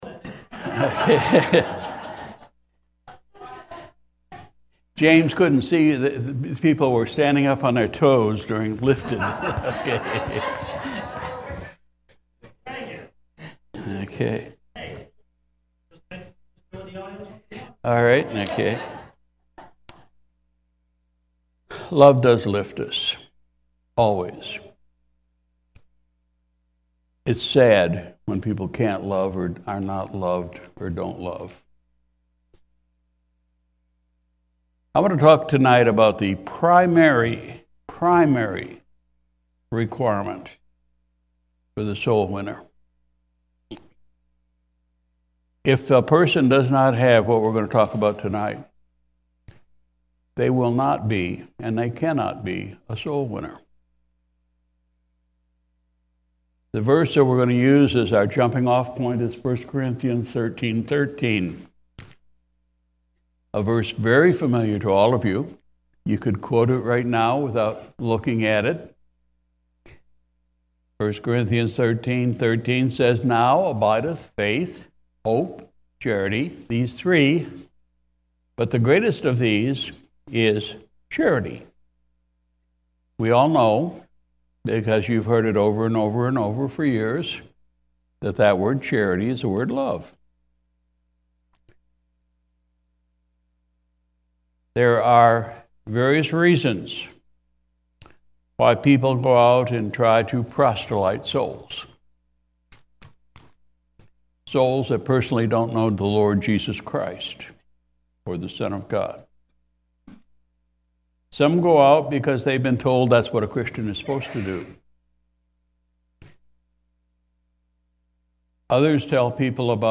Soul Winning part 2 , Sunday Evening Service